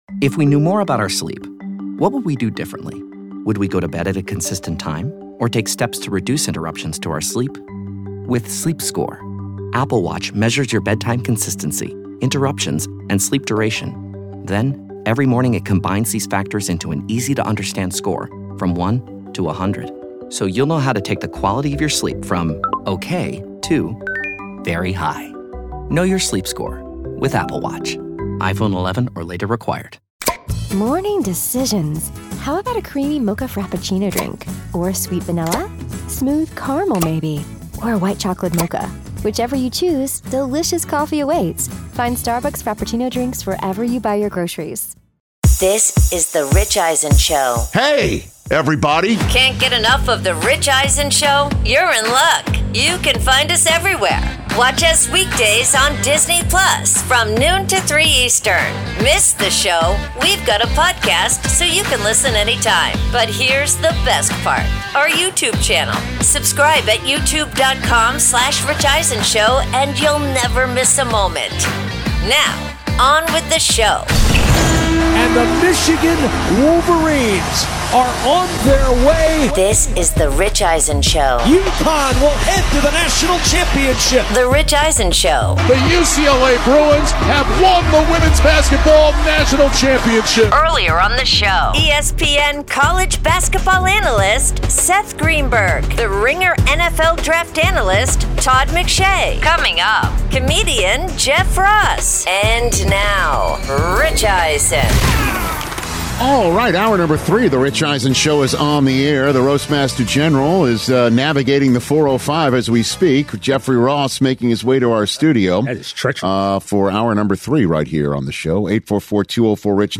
Hour 3: UNC Hires Michael Malone, plus Comedian/Roastmaster General Jeff Ross In-Studio